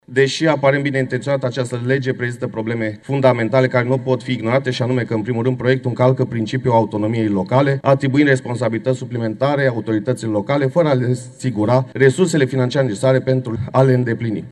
Nini Pascalini, deputat AUR: „Această lege prezintă probleme fundamentale care nu pot fi ignorate”